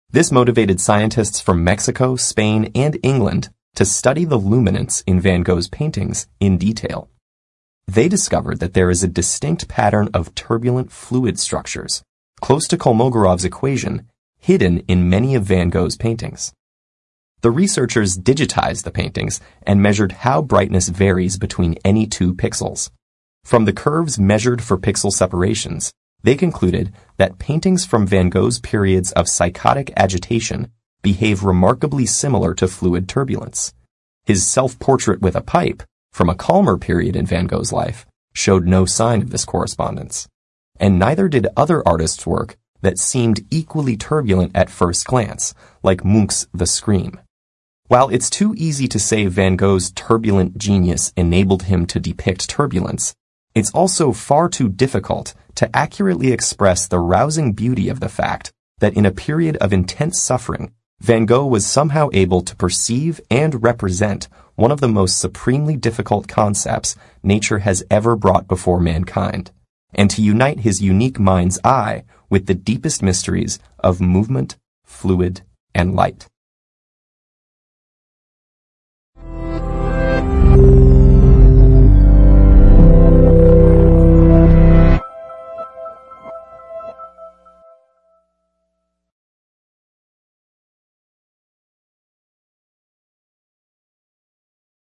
TED演讲:梵高《星月夜》背后隐藏的数学理论(3) 听力文件下载—在线英语听力室